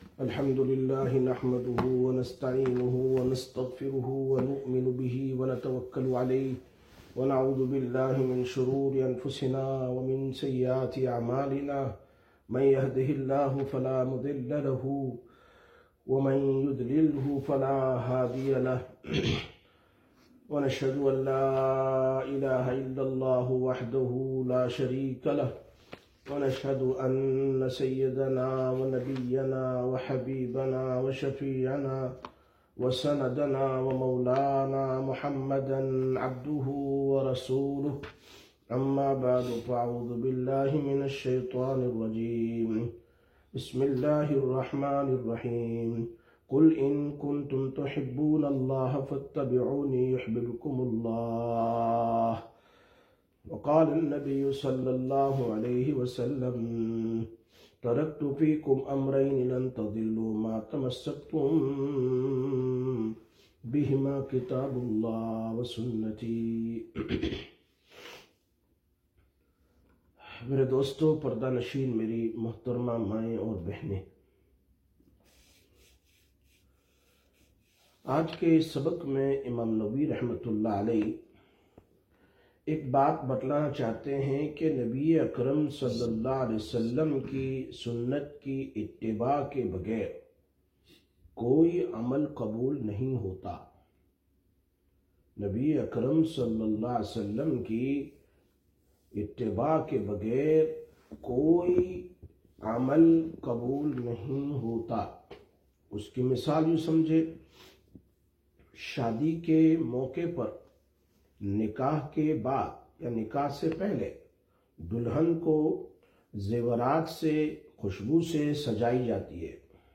17/09/2025 Sisters Bayan, Masjid Quba